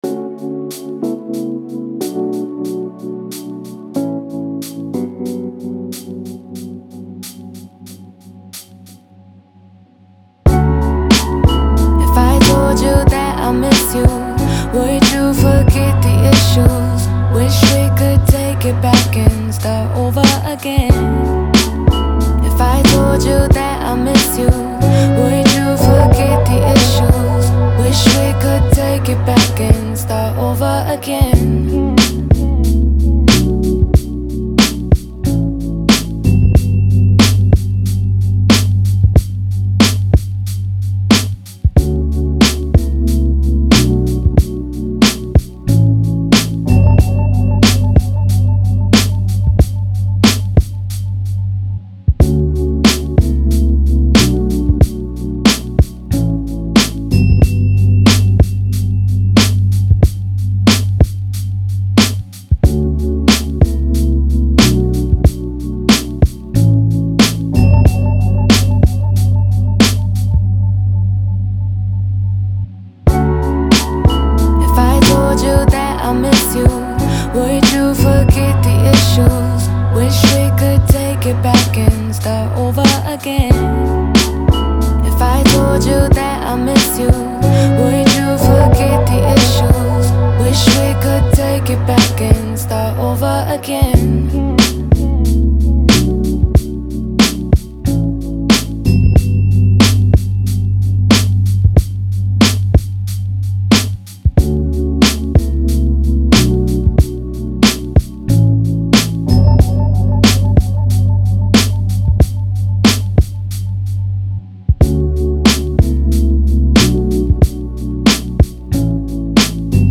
Description : HIp Hop/R&B